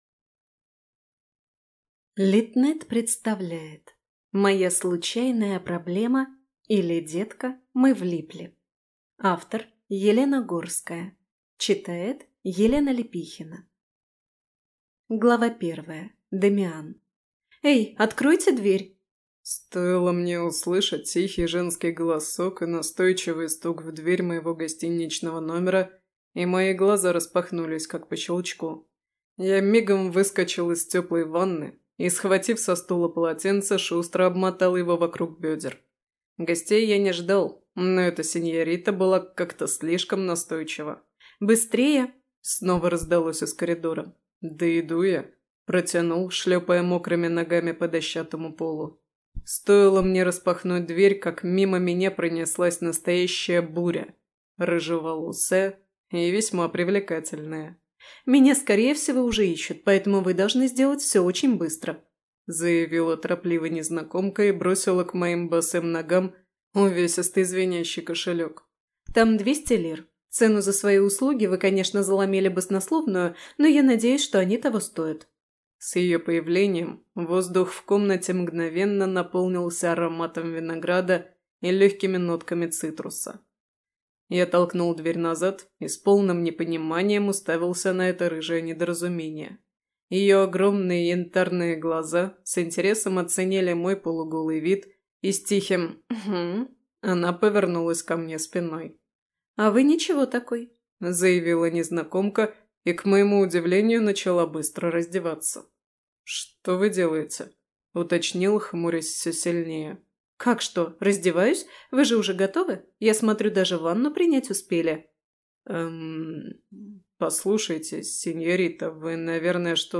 Цыпленок Лисы Патрикеевны (слушать аудиокнигу бесплатно) - автор Дарья Донцова